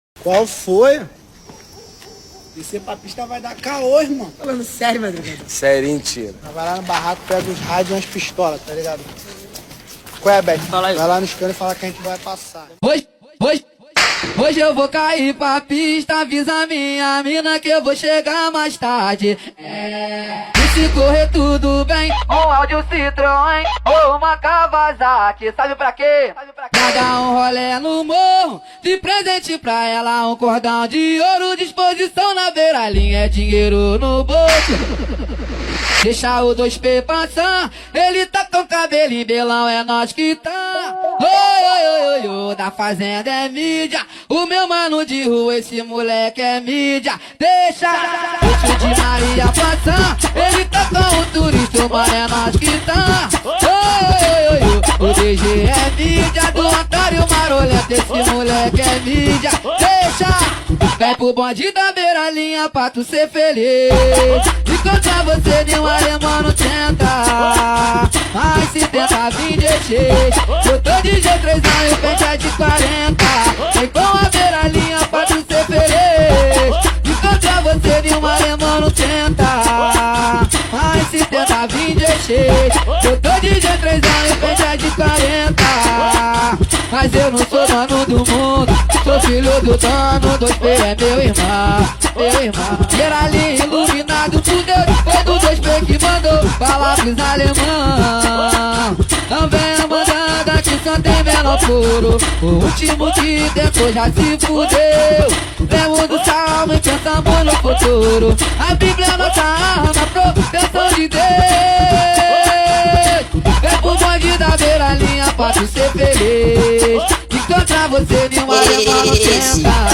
2024-07-08 00:51:30 Gênero: Funk Views